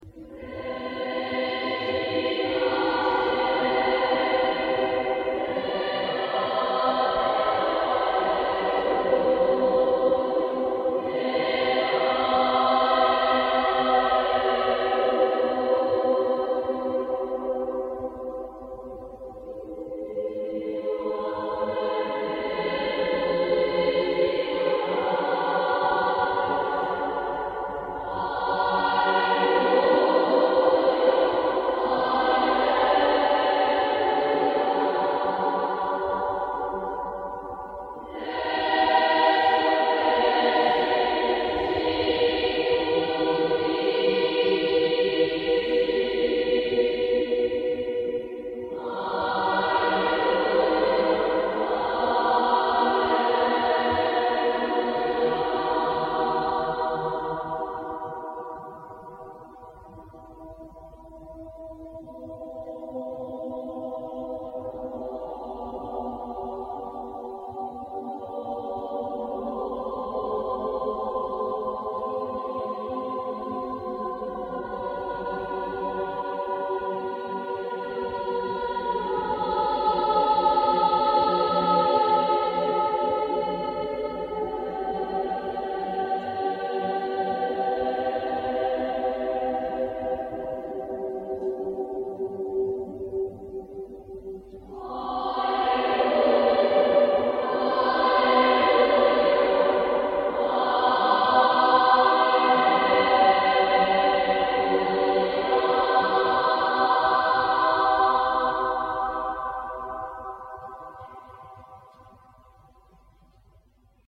Grabaciones históricas realizadas
de varios cantos de la escolanía